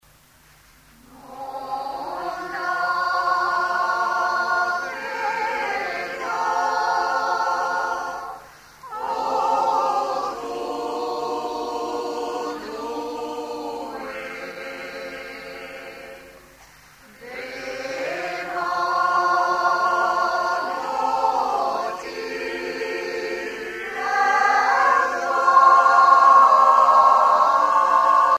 Informateur(s) Mor-Gan Chorale
Genre strophique
Chansons de la soirée douarneniste 88
Pièce musicale inédite